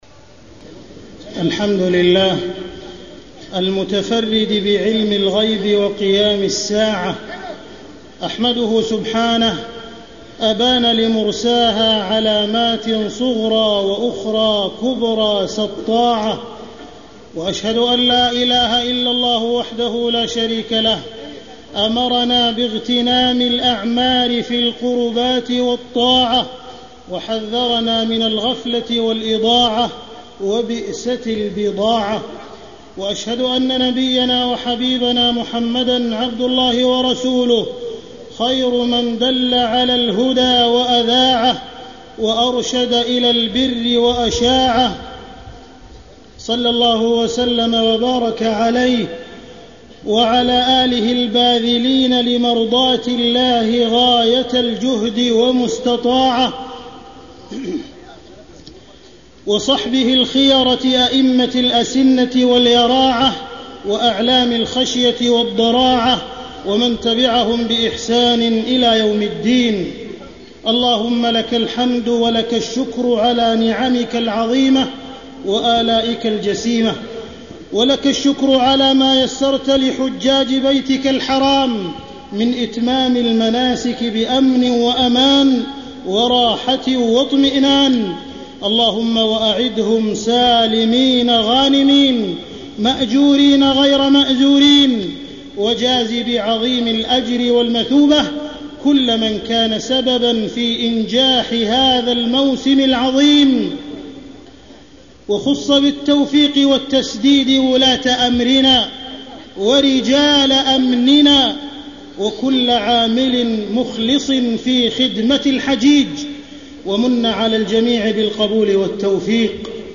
تاريخ النشر ٢١ ذو الحجة ١٤٢٧ هـ المكان: المسجد الحرام الشيخ: معالي الشيخ أ.د. عبدالرحمن بن عبدالعزيز السديس معالي الشيخ أ.د. عبدالرحمن بن عبدالعزيز السديس أشراط الساعة بين أيدينا